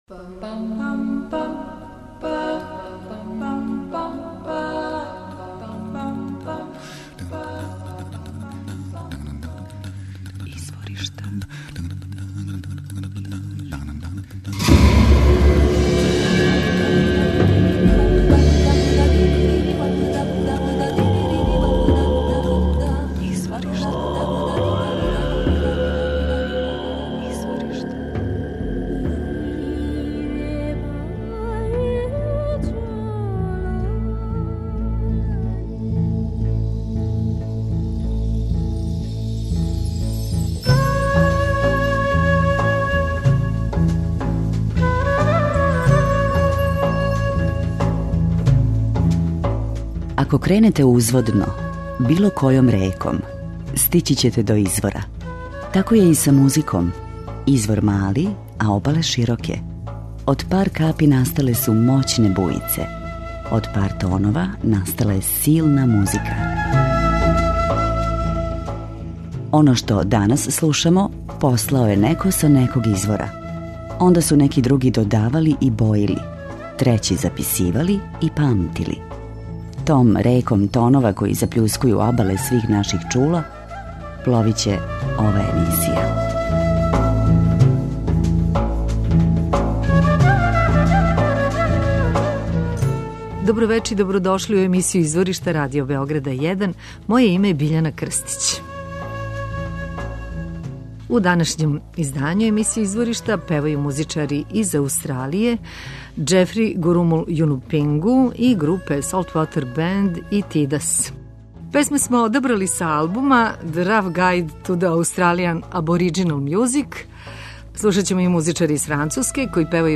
аустралијски музичари инспирисани традицијом Абориџина